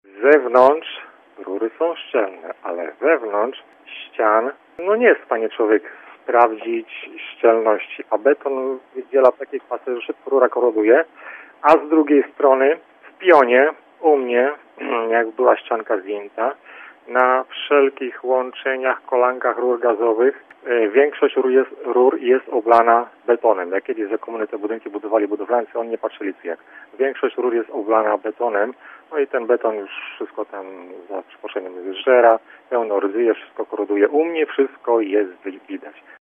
Jak mówi nam jeden z słuchaczy rury gazowe w wieżowcu przy ulicy Marcinkowskiego 7c wymagają wymiany. Po 50 latach całe pokryte są rdzą.